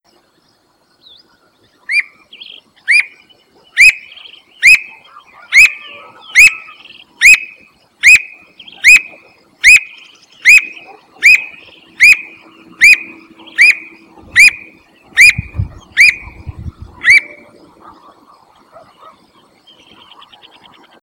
Piaya cayana macroura - Pirincho de monte
pirinchodemonte.wav